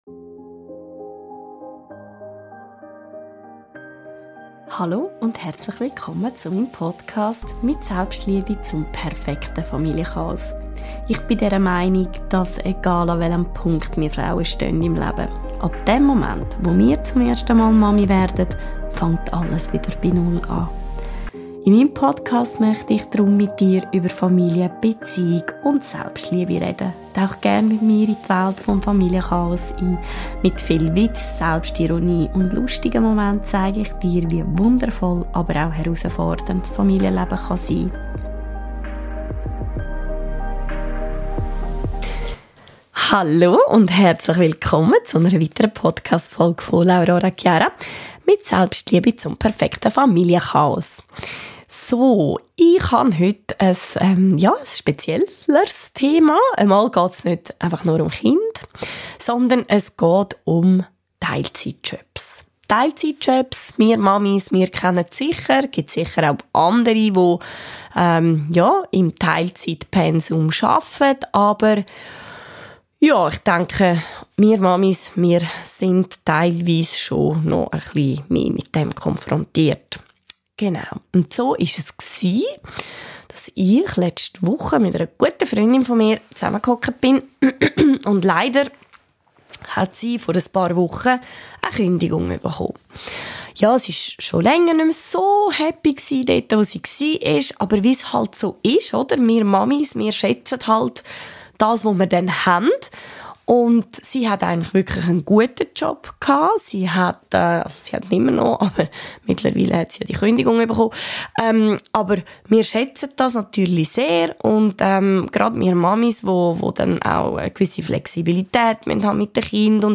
Wir haben uns darüber unterhalten wieso wir uns eine Jobabsage manchmal so sehr zu Herzen nehmen oder uns sogar so darüber nerven können.